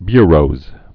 (byrōz)